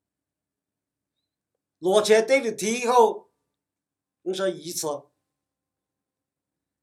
244擂捶跌落地下